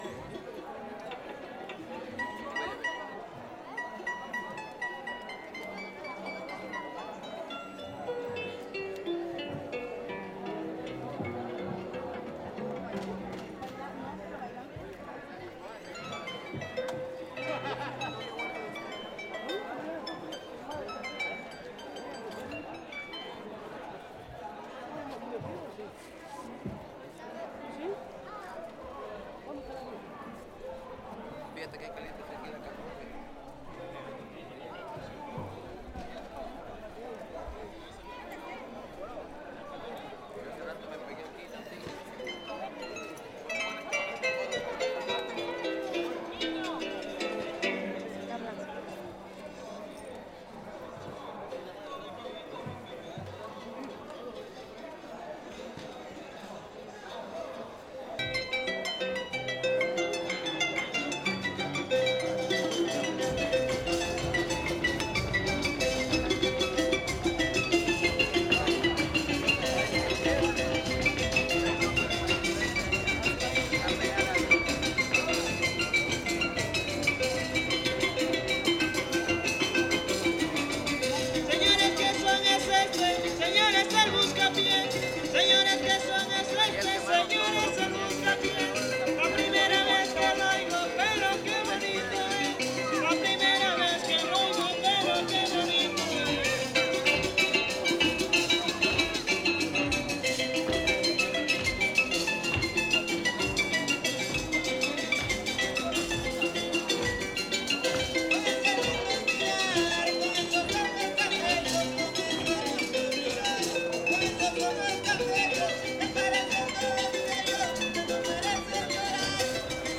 Concurso Estatal de Fandango